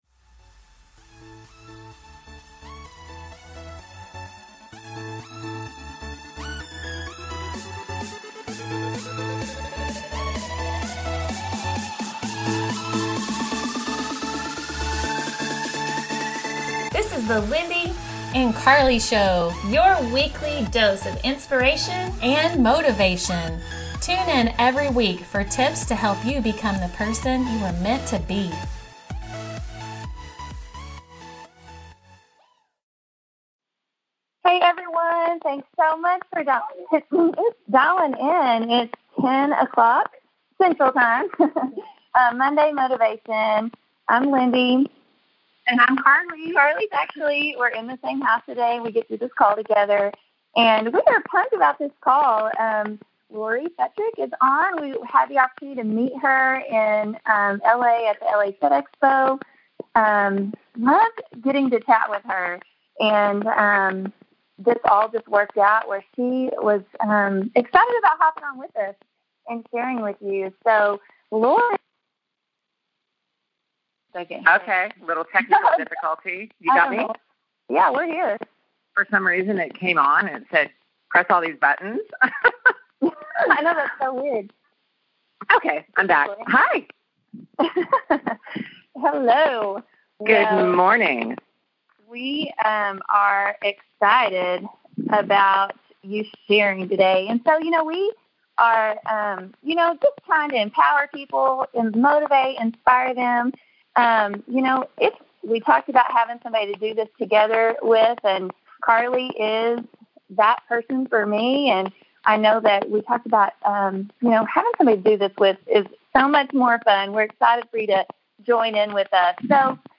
Episode 21 - Interview with Special Guest Lori “Ice” Fetrick
Lori Fetrick, a.k.a. “Ice” from TV’s American Gladiator, joins us for our 1st interview. She shares her tips of achieving goals and how to stay motivated in the process!